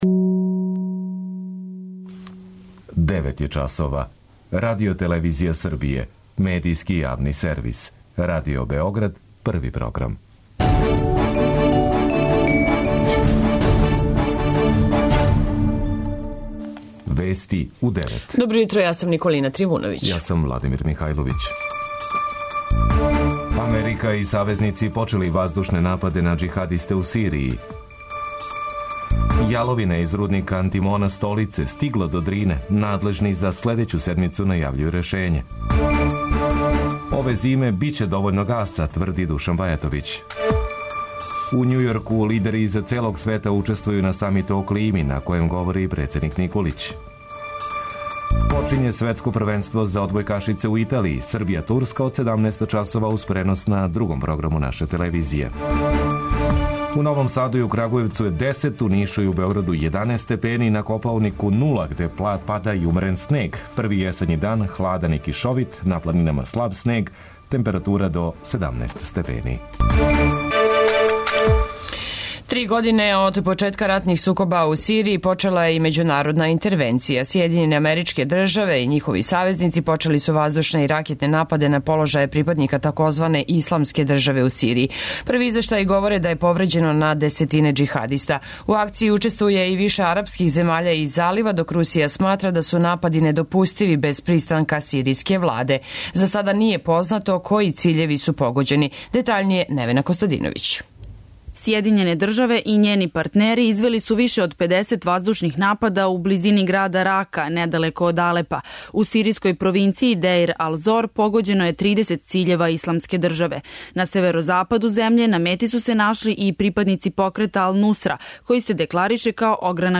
преузми : 10.29 MB Вести у 9 Autor: разни аутори Преглед најважнијиx информација из земље из света.